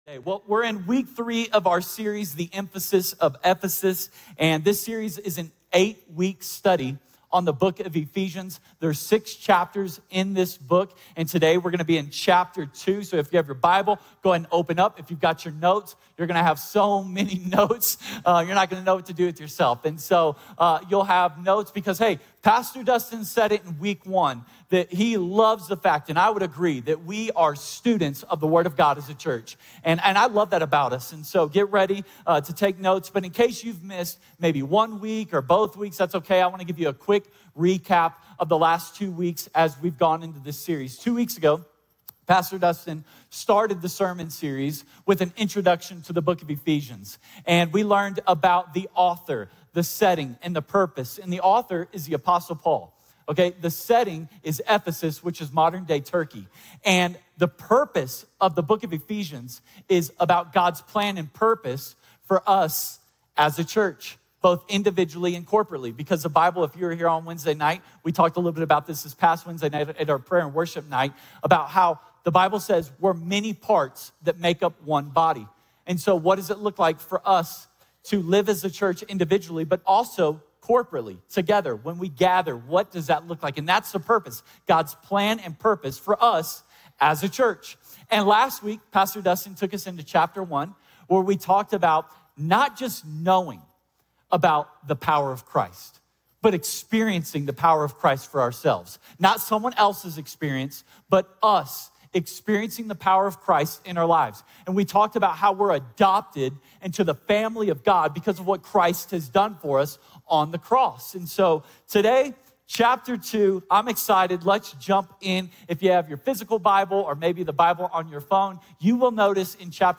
A message from the series "The Emphasis of Ephesus." In this week's message, we uncover the historical significance of Ephesus and how Paul's teachings still speak to us today.